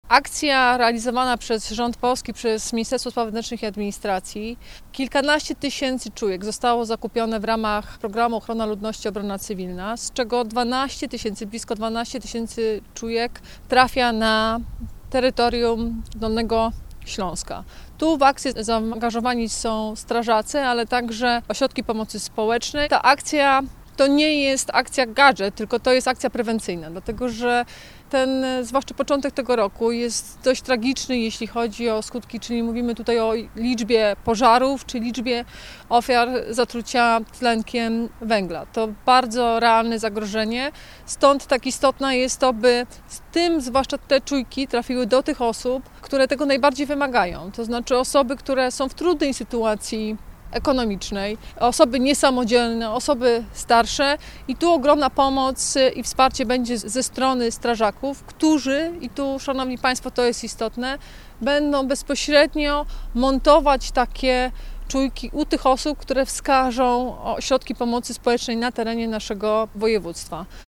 Mówi Anna Żabska – wojewoda dolnośląska.